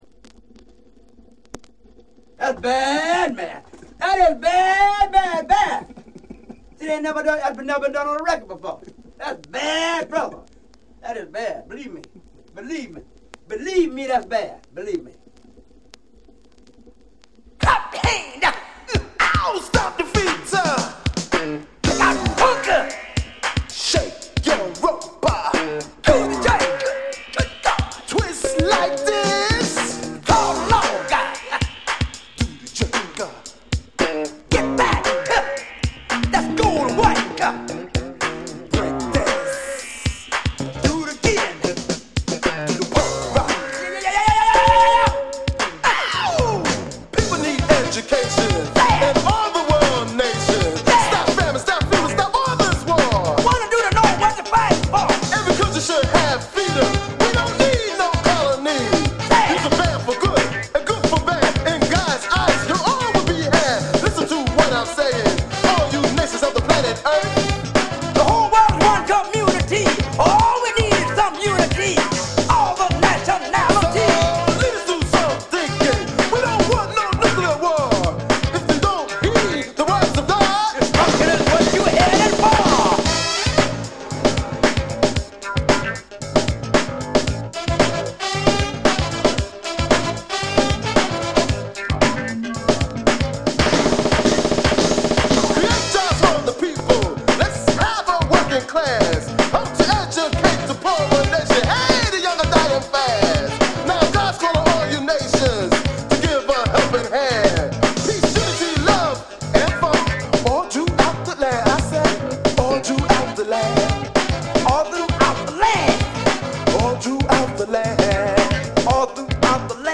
> HIPHOP/R&B
オールドスクールの金字塔！